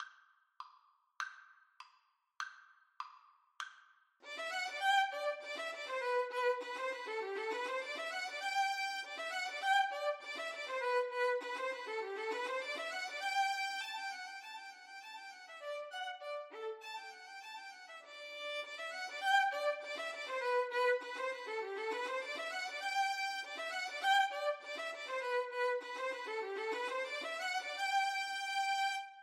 Violin 1Violin 2
2/4 (View more 2/4 Music)
Allegro (View more music marked Allegro)
Classical (View more Classical Violin Duet Music)